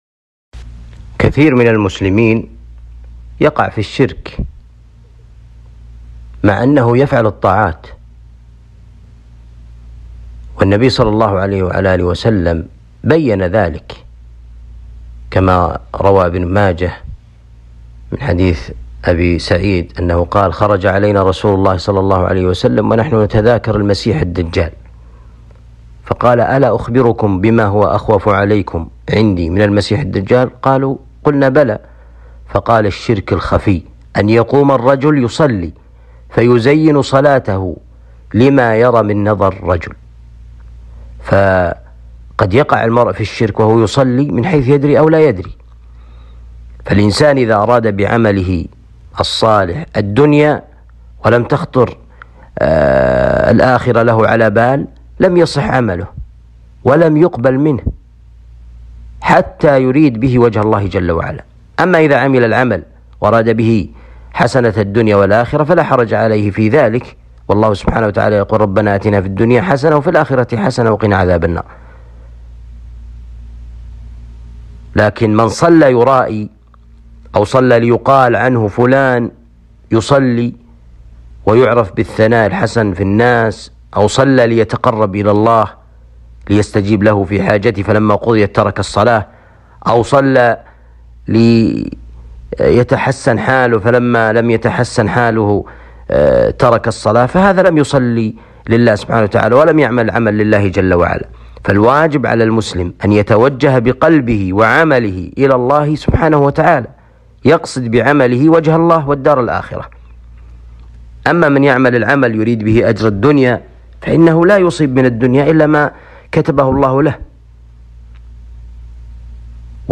نصيحة وتوجيه لمن يرائي الناس في العبادة أسئلة لقاء اليوم المفتوح